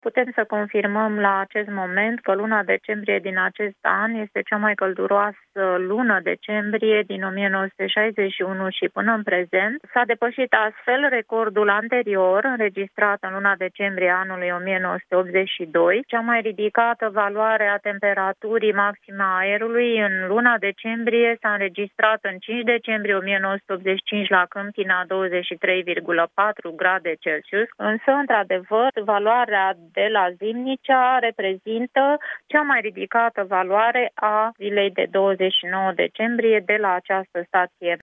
Directorul Administrației Naționale de Meteorologie, Elena Mateescu: